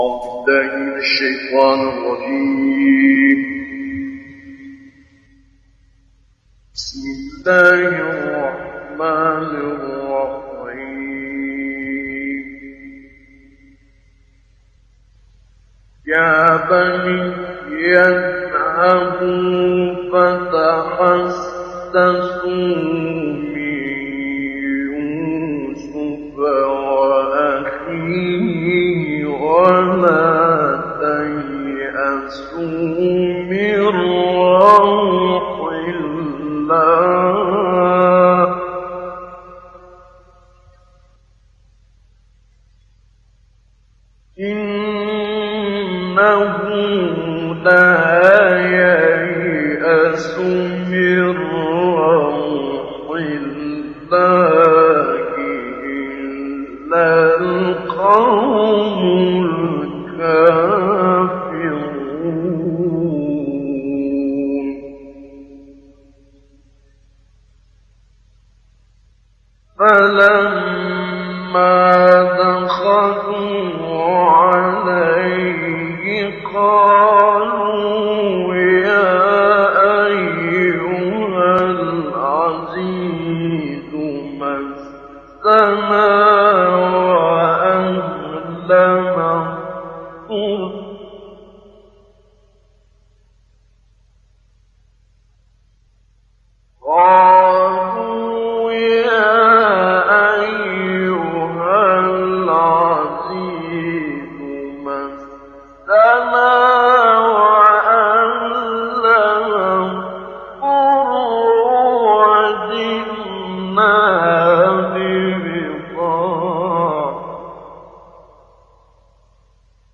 گروه فعالیت‌های قرآنی ــ ابوالعینین شعیشع دارای صوت بسیار قوی، زنگ‌دار و مساحت صوتی کامل بوده و تحریر‌های فوق‌العاده ریز و دندانه‌دار داشته است. در مجموع وی تلاوتی حزین، خاشع و در عین حال بسیار محکم دارد.
تلاوت آیات سوره مبارکه یوسف توسط ابوالعینین شعیشع
یادآور می‌شود این تحلیل در برنامه «اکسیر» از شبکه رادیویی قرآن پخش شد.